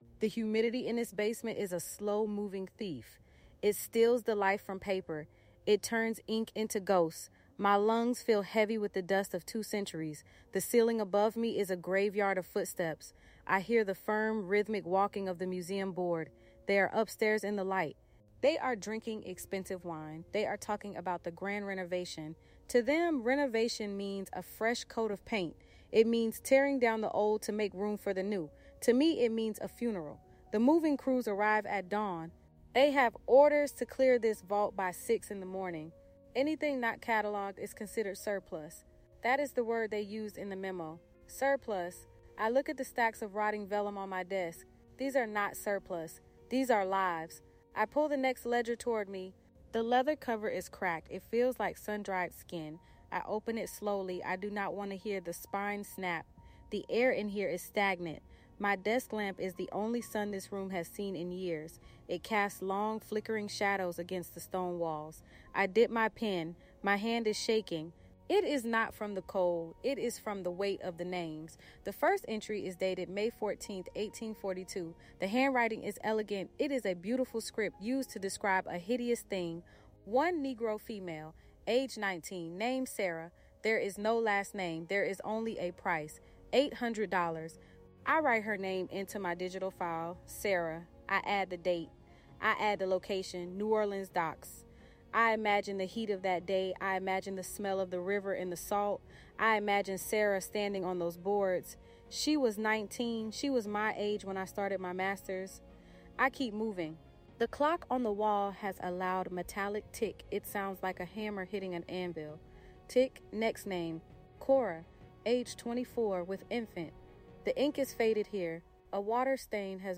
This first-person narrative explores the weight of the archives and the personal cost of bearing witness to a painful past.